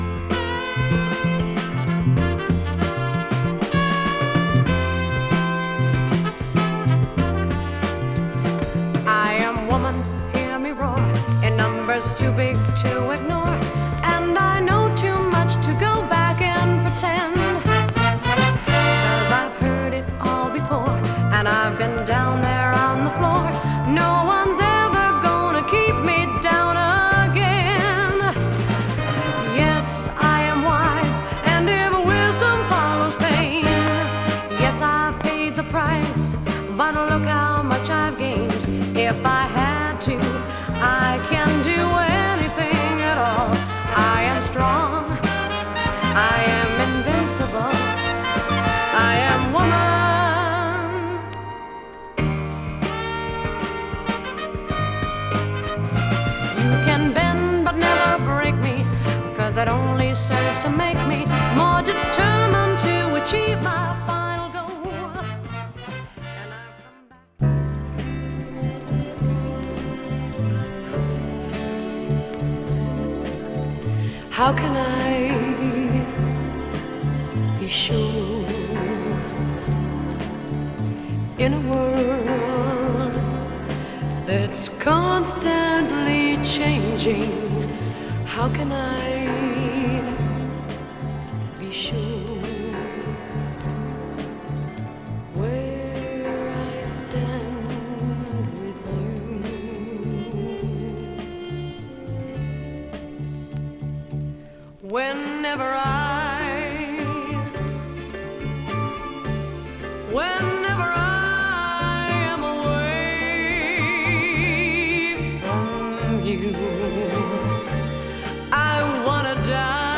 スモーキーでメロウ・ジャジーな解釈のタイトル曲。